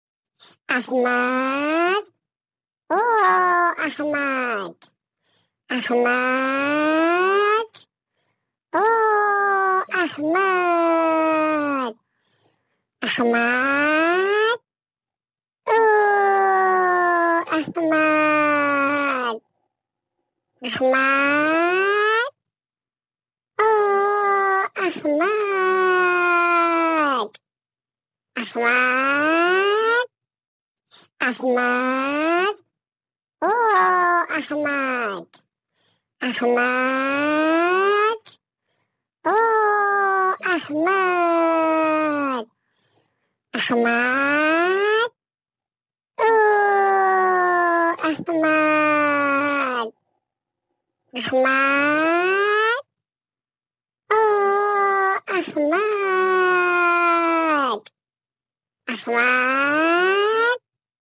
Goat Calling AHMAD Name Sound Effects Free Download